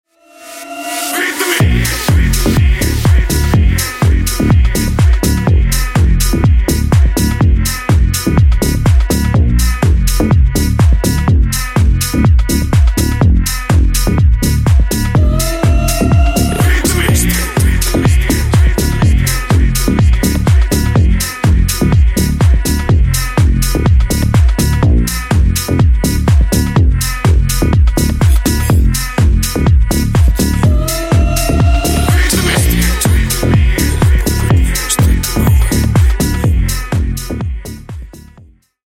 Клубные Рингтоны
Рингтоны Электроника